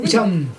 [ut͡ɕam] noun bride